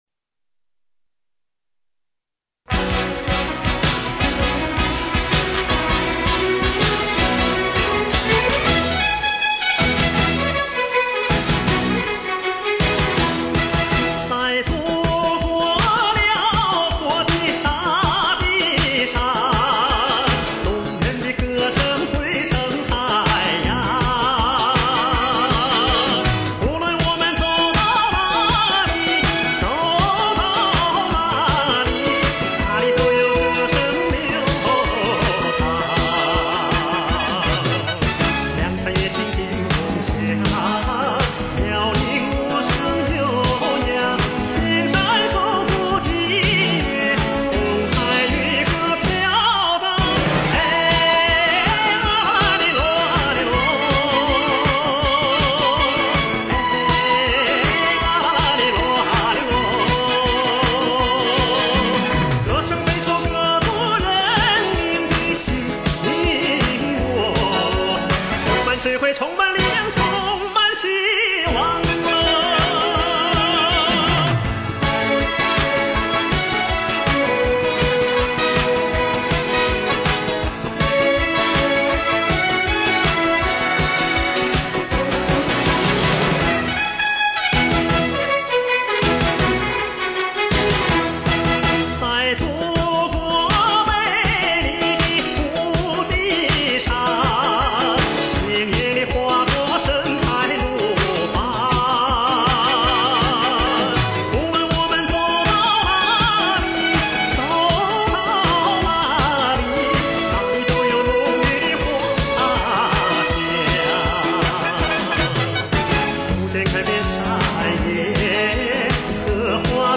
音乐类型：华语流行/男歌手